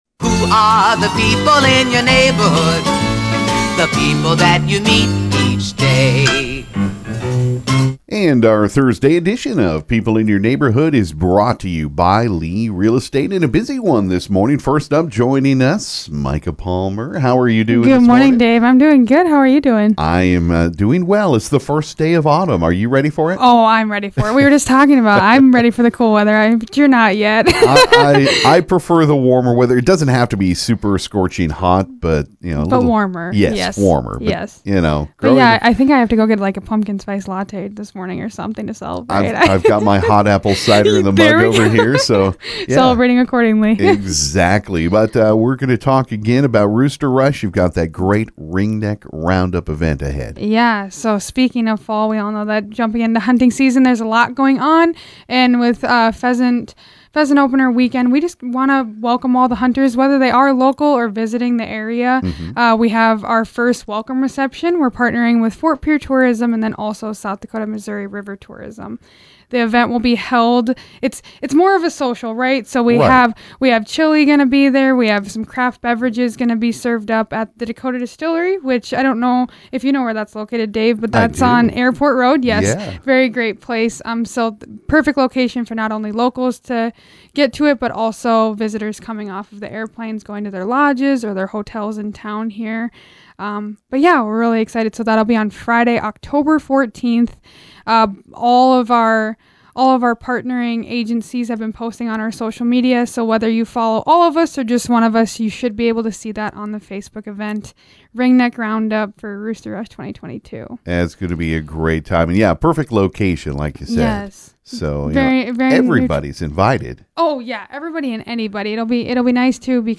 This morning was busy on KGFX for People In Your Neighborhood.